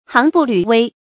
行不履危 xíng bù lǚ wēi
行不履危发音
成语注音 ㄒㄧㄥˊ ㄅㄨˋ ㄌㄩˇ ㄨㄟ